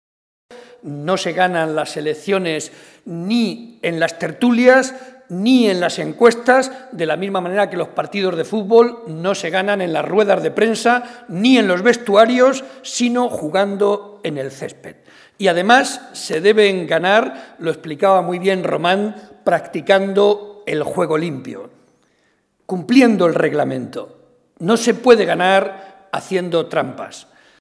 “De la misma manera que en el fútbol hasta el minuto final se puede dar la vuelta al partido, también se le puede dar la vuelta a las encuestas y a los pronósticos” ha asegurado el cabeza de lista del PSOE al Congreso, José María Barreda, durante su intervención en un acto público en la localidad de Miguelturra.
Cortes de audio de la rueda de prensa